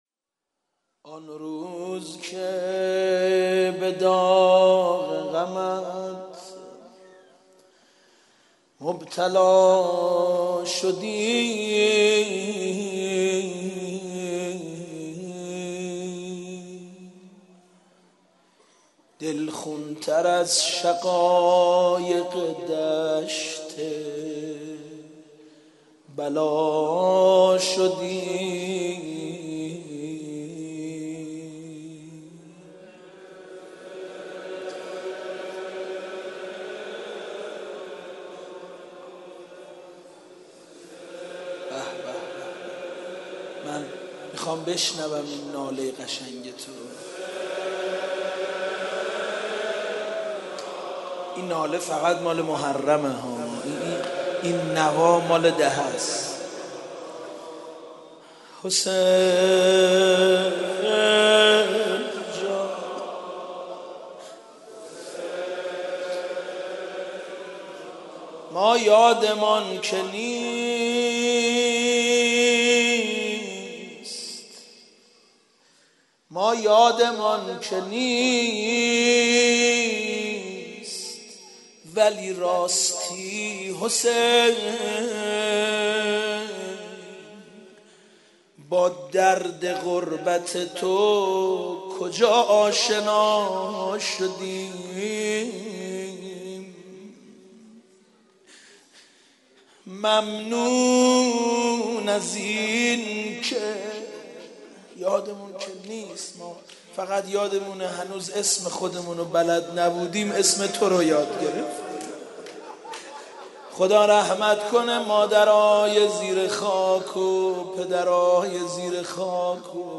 شب دوم محرم